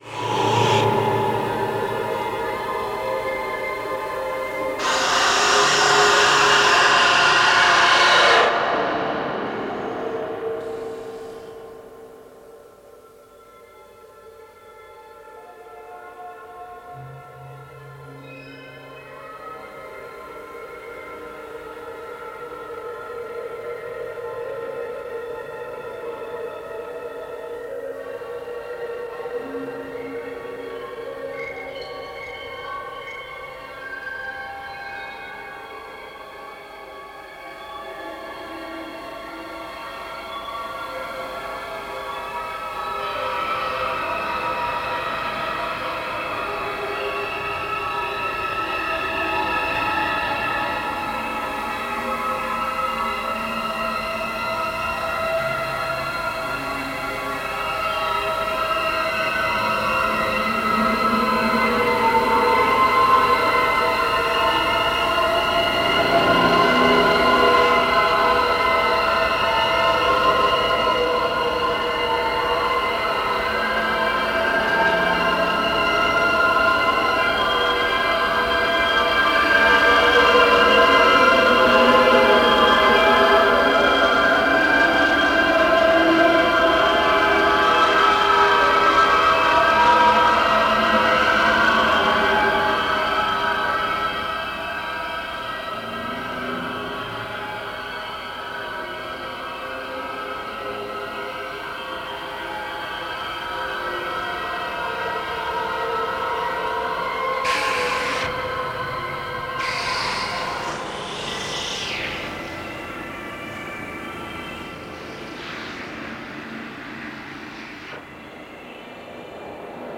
longform electroacoustic composition